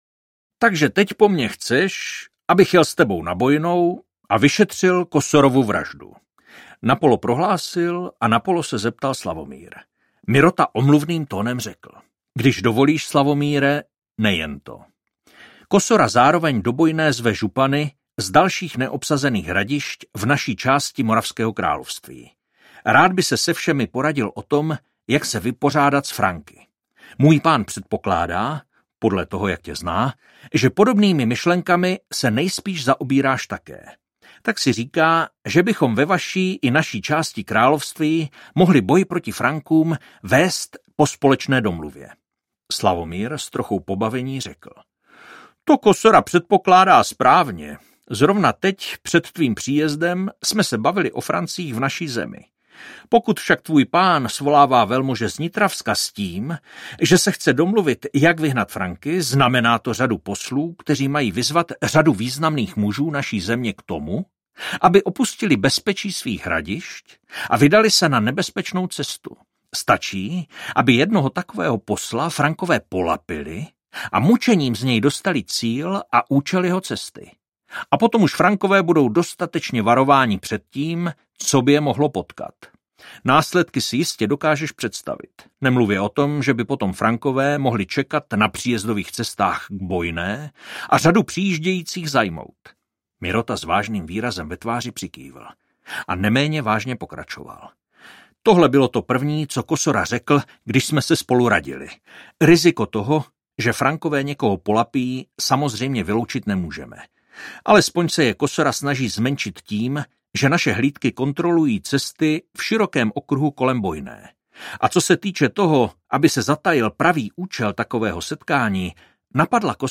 Případ kněze z Bojné audiokniha
Ukázka z knihy